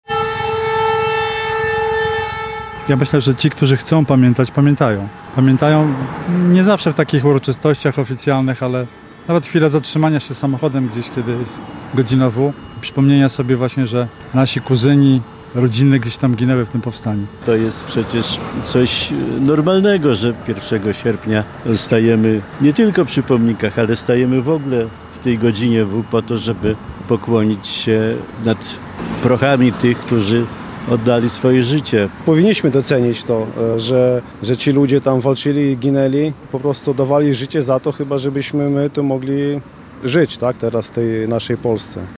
W godzinę 'W” w mieście rozległy się syreny: